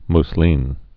(ms-lēn)